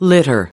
19. litter (v) /ˈlɪtər/: xả rác